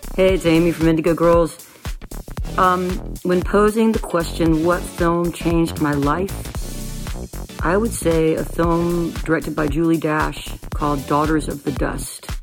(captured from the vimeo livestream)
04. talking with the crowd (amy ray) (0:12)